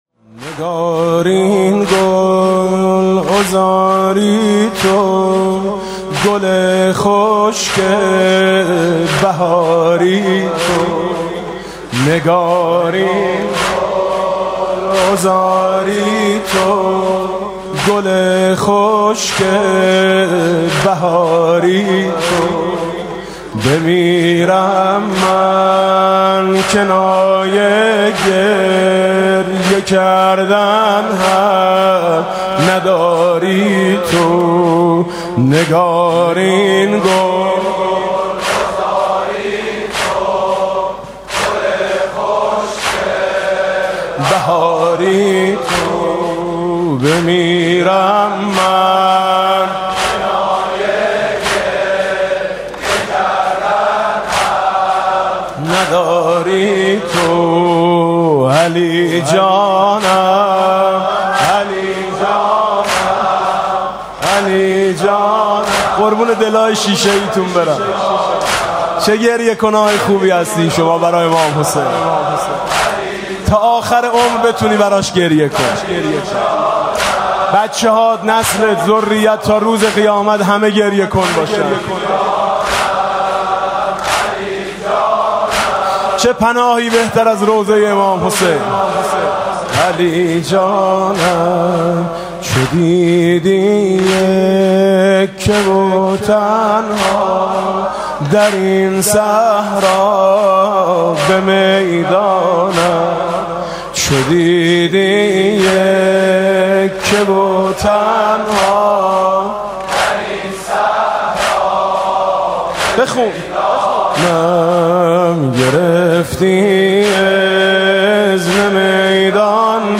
نوحه سنّتی